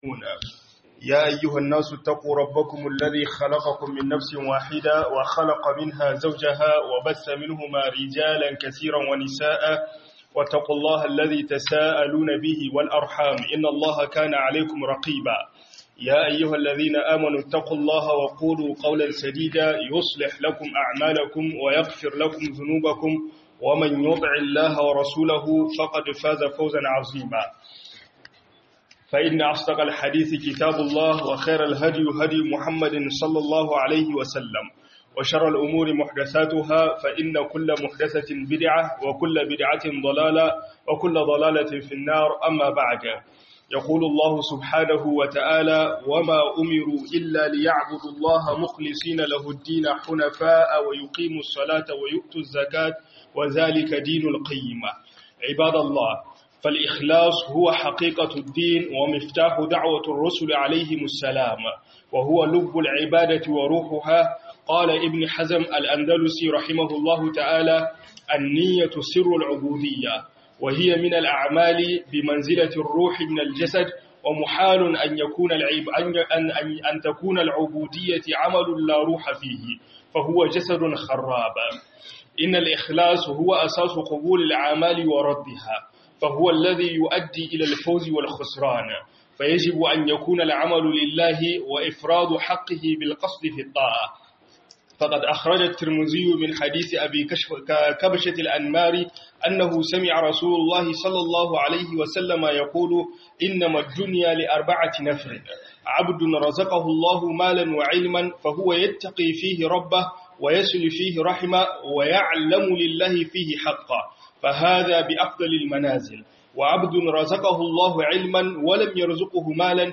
YIN AIKI DAN ALLAH - HUDUBA